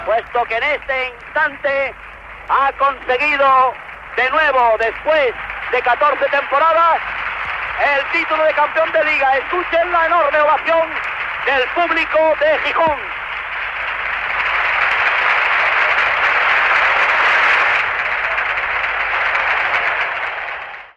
Final del partit.
Esportiu